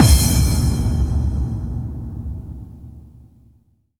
VEC3 FX Reverbkicks 03.wav